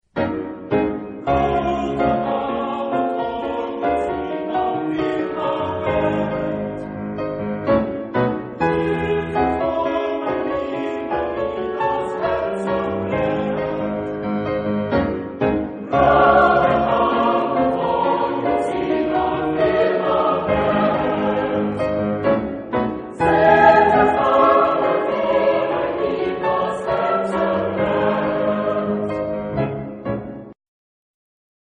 Genre-Stil-Form: weltlich ; romantisch ; Lied
Chorgattung: SATB  (4 gemischter Chor Stimmen )
Instrumente: Klavier (1)